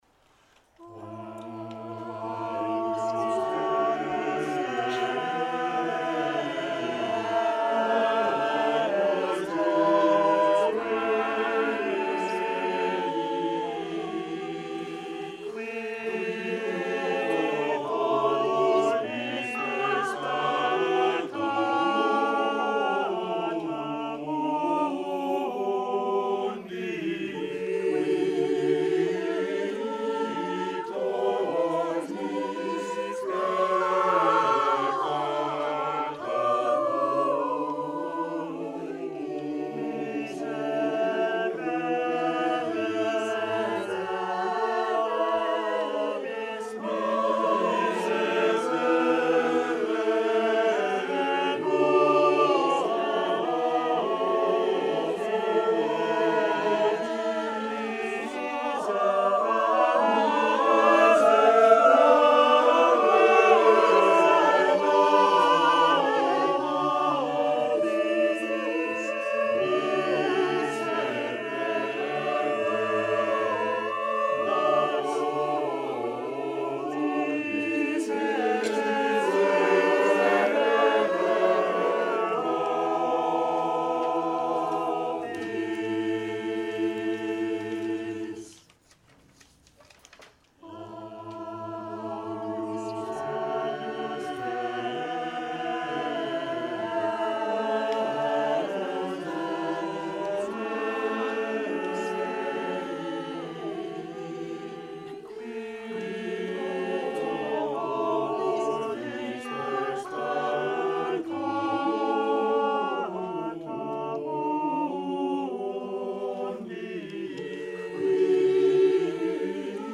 The Renaissance Street Singers' 45th-Anniversary Loft Concert, 2018